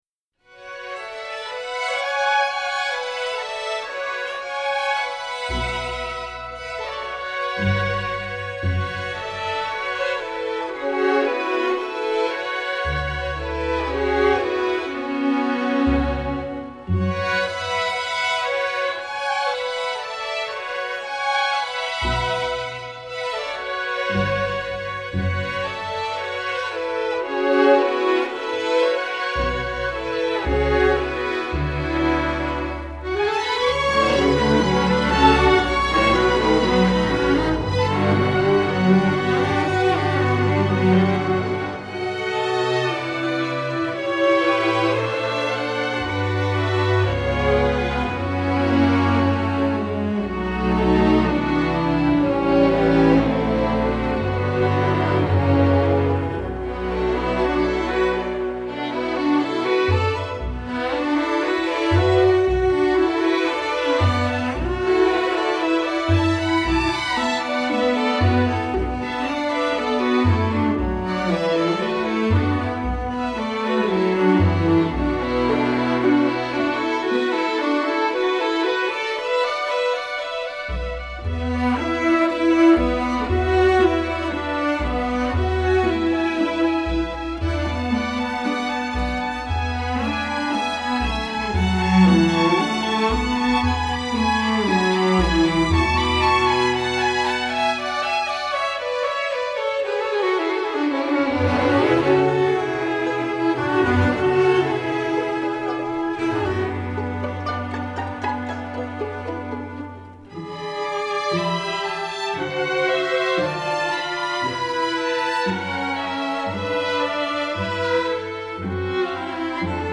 Composer: Yunnan Folk Song
Voicing: String Orchestra